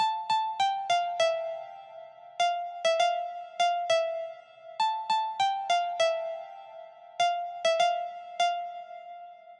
钢琴的旋律
描述：悲伤、情绪化的钢琴旋律，适合说唱体裁，我认为
Tag: 100 bpm Rap Loops Piano Loops 1.62 MB wav Key : Unknown